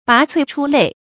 拔萃出類 注音： ㄅㄚˊ ㄘㄨㄟˋ ㄔㄨ ㄌㄟˋ 讀音讀法： 意思解釋： 同「出類拔萃」。